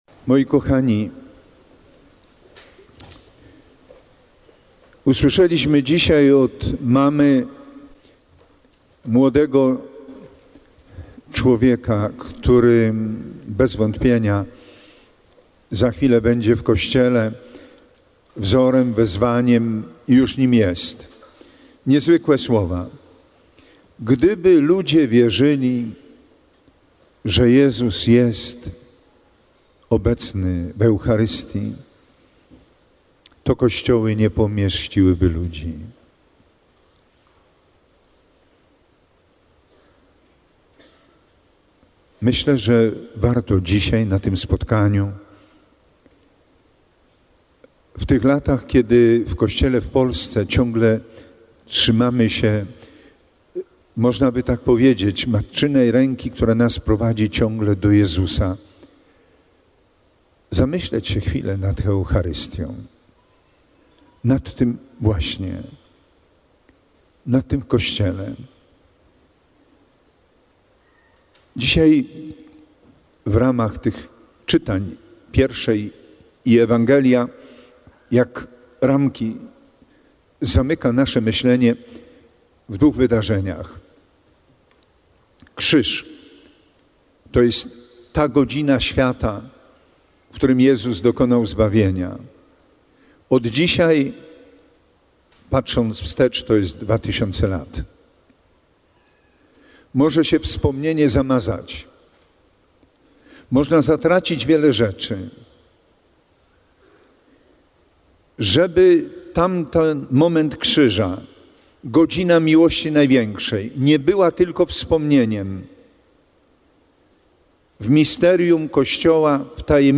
Diecezjalne Spotkanie Młodych zakończyło się uroczystą Mszą św. pod przewodnictwem bp. Edwarda Dajczaka. Hierarcha w słowach homilii przestrzegał młodzież przed różnymi formami indywidualizowania wiary.